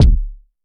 Kick (Day N Nite).wav